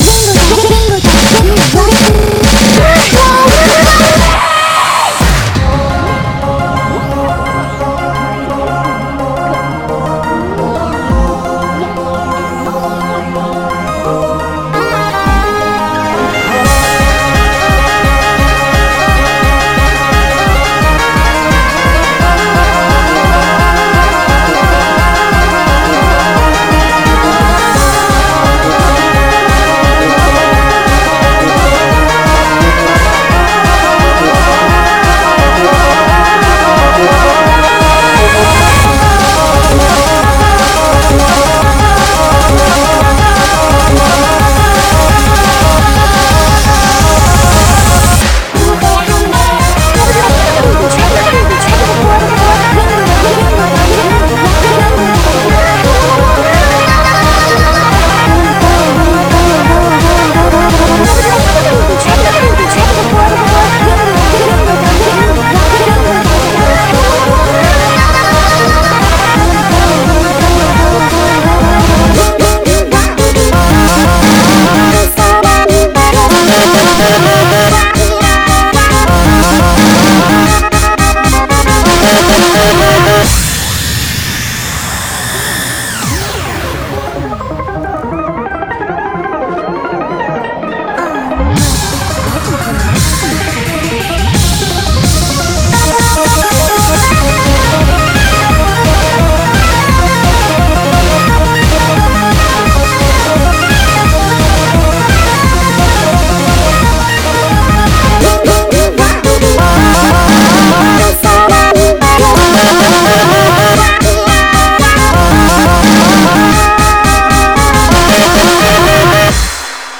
BPM173
Audio QualityPerfect (High Quality)
CommentairesGreat remix. :3